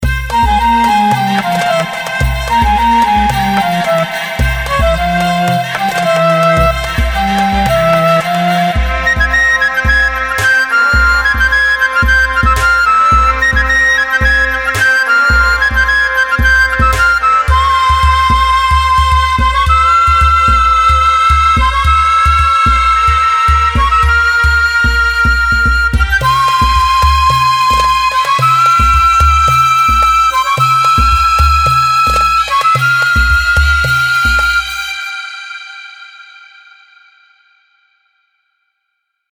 Turkish Flute
Designed with precision and authenticity, this flute delivers expressive dynamics, rich harmonics, and intricate ornamentations characteristic of Middle Eastern and Anatolian music.
Turkish-Flute.mp3